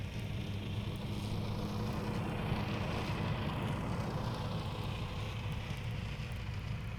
Zero Emission Subjective Noise Event Audio File - SAE J192 (WAV)